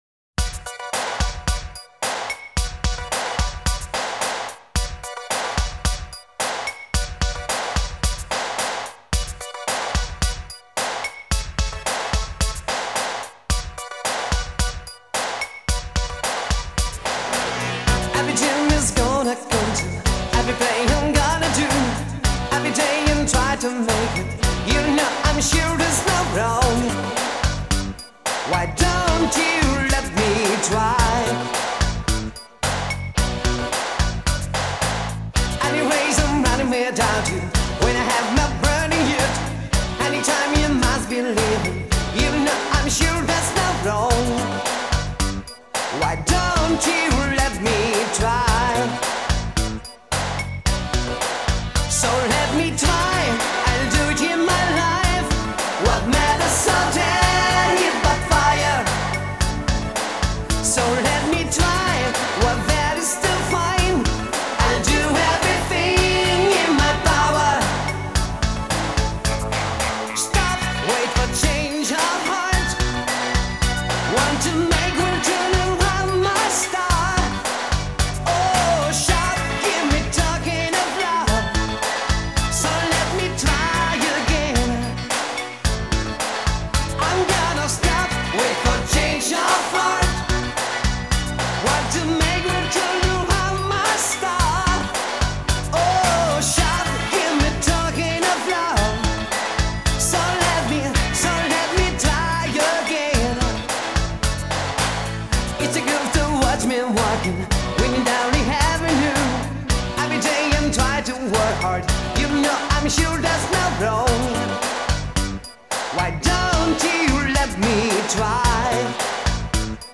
- это уже классический евробит